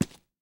footsteps
rails-09.ogg